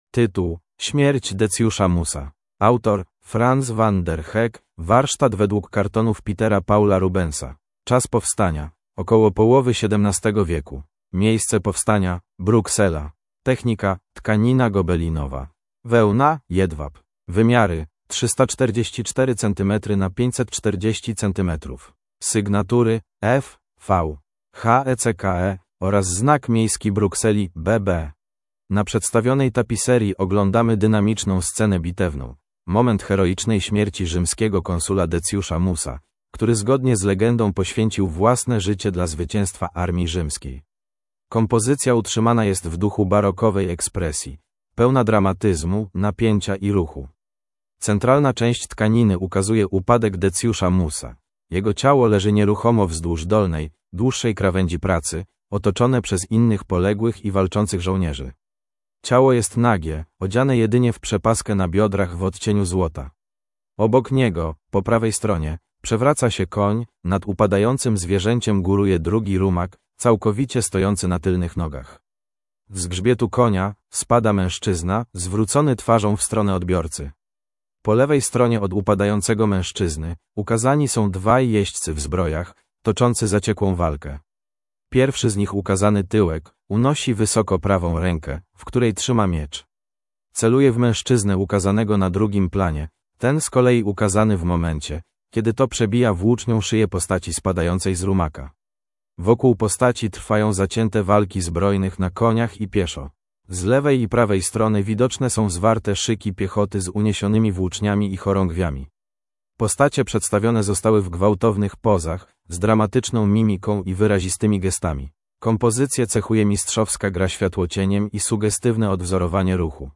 MNWr_audiodeskr_Smierc_Decjusza_Musa.mp3